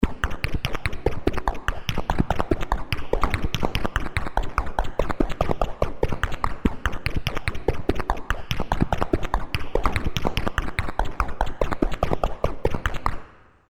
Wet Tics.wav